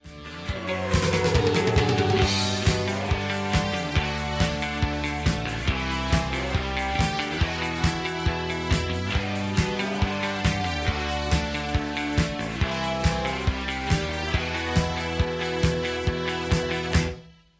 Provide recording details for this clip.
This is a sound sample from a commercial recording.